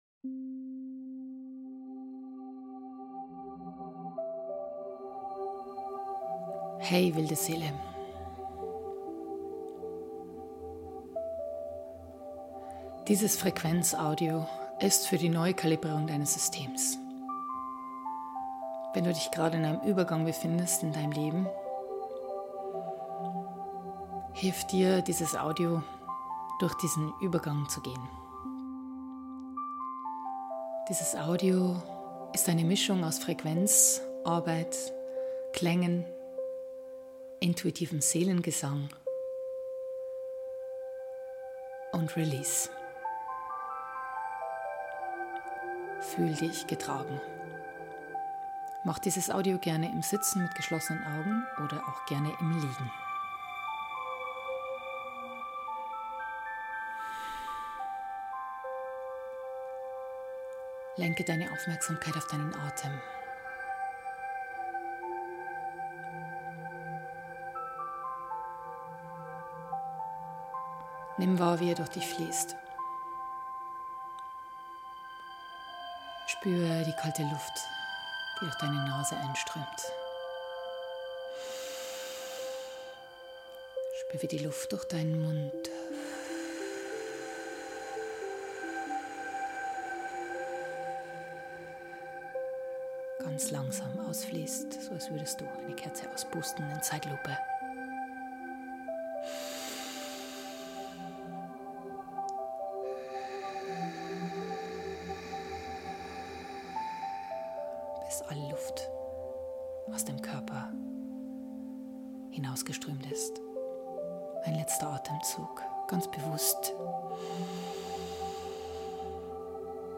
Ich selbst bin durch einen starken Übergangsprozess gegangen in den letzten Monaten. Dies Frequenzsession mit eigens komponierten Seelenklang und Seelengesang kann dich in Übergangsphasen unterstützen.